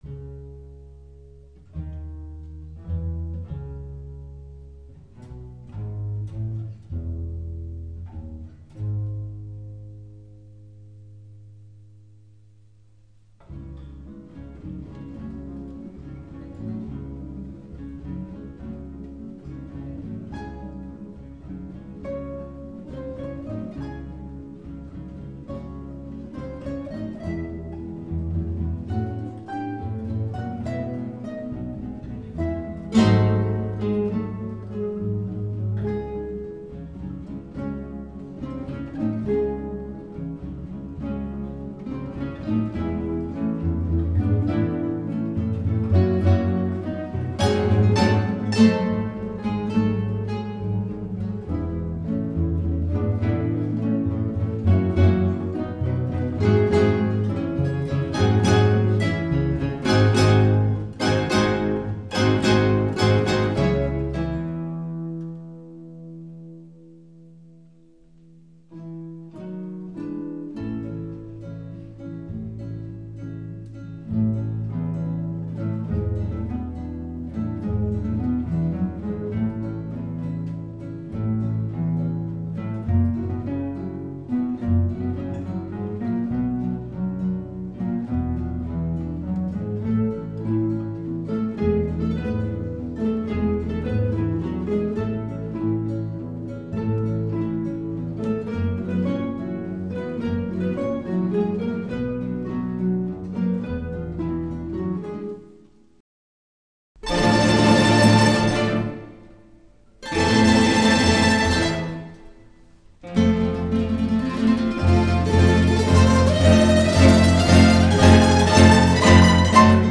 Musik für Gitarrenorchester